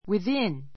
within A2 wiðín ウィ ずィ ン 前置詞 ～以内に[で]; ～の範囲 はんい 内に[で] within a week within a week 1週間以内に I live within ten minutes' walk of the school.